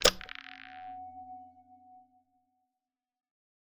Minecraft Version Minecraft Version 1.21.5 Latest Release | Latest Snapshot 1.21.5 / assets / minecraft / sounds / block / lodestone / lock1.ogg Compare With Compare With Latest Release | Latest Snapshot
lock1.ogg